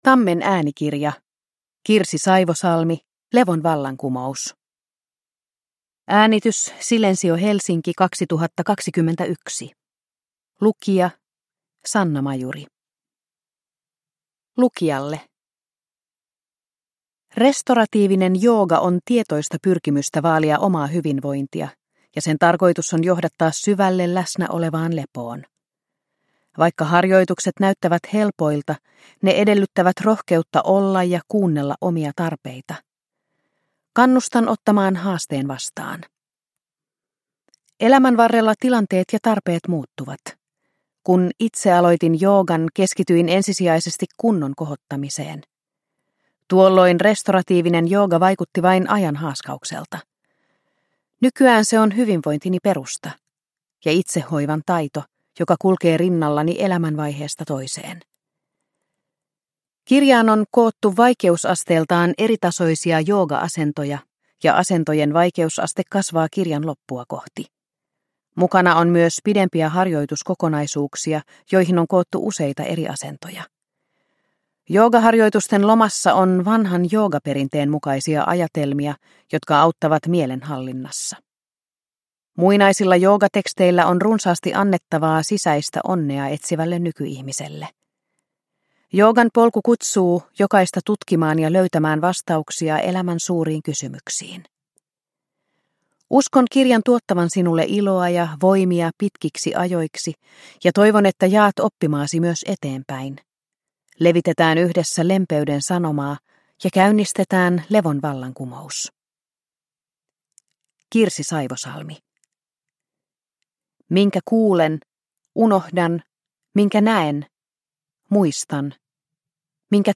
Levon vallankumous – Ljudbok – Laddas ner